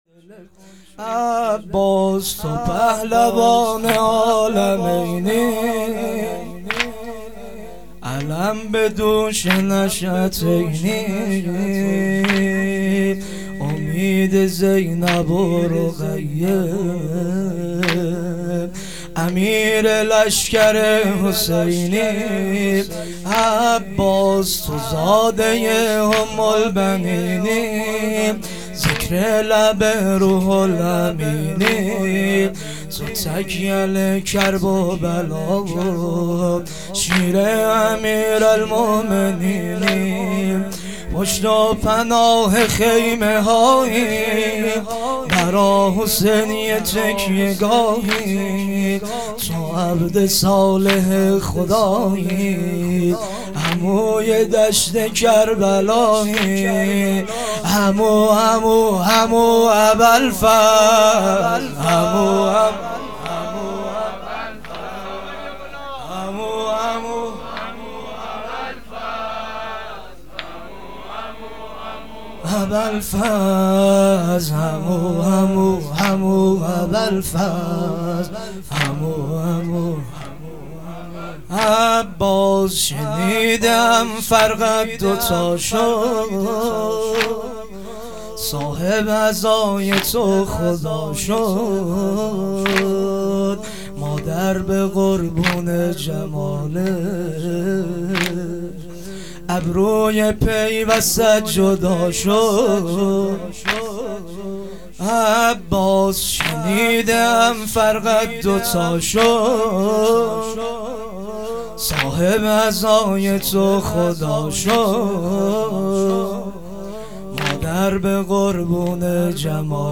دهه دوم محرم ۹۸.شب دوم